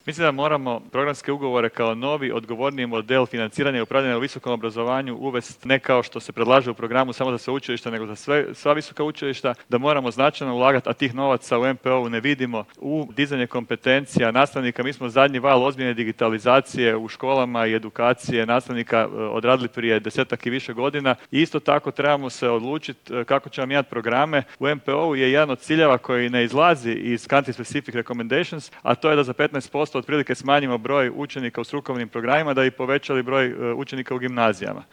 ZAGREB - Ususret donošenju Nacionalnog plana za oporavak i otpornost u organizaciji HUP-a održana je konferencija ''Kakve nas investicije mogu izvući iz krize'' na kojoj su sugovornici koji dolaze iz realnih sektora govorili o preduvjetima potrebnim za oporavak od krize uzrokovane pandemijom koronavirusa, kao i o tome što je domaćoj industriji potrebno da dosegne svoj puni investicijski potencijal.